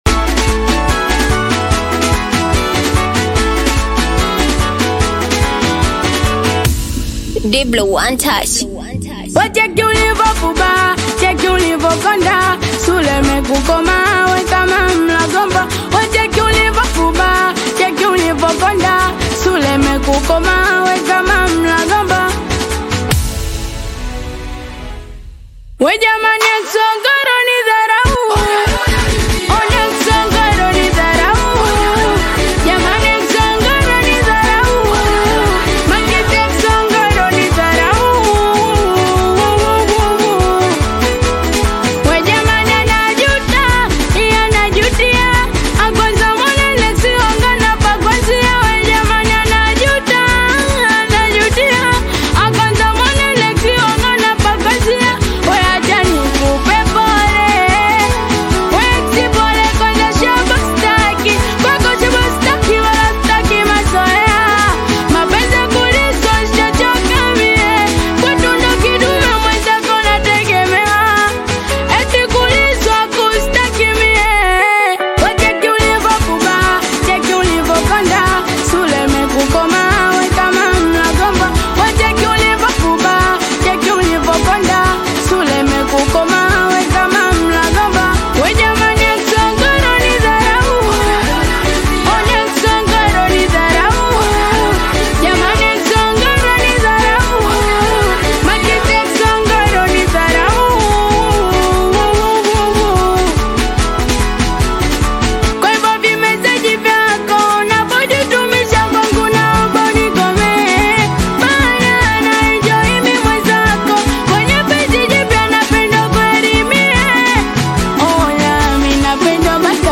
Singeli music track
Bongo Flava singeli